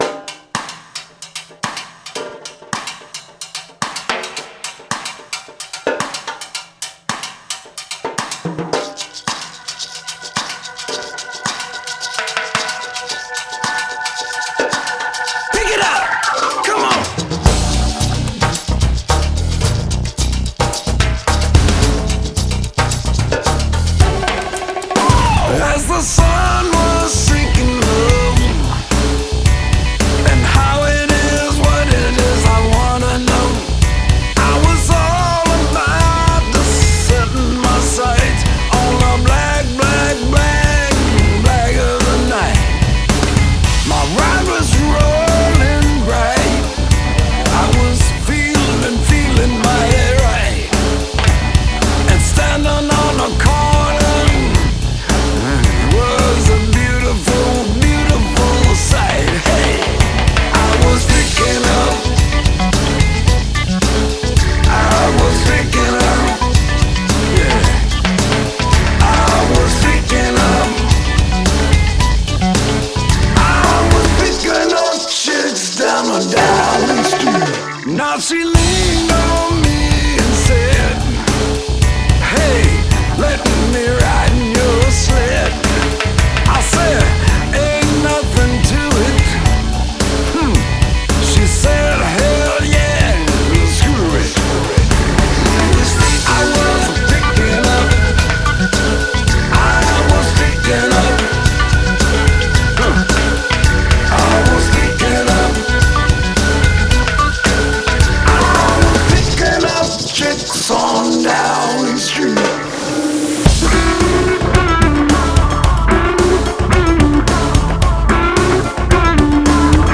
Жанр: Blues Rock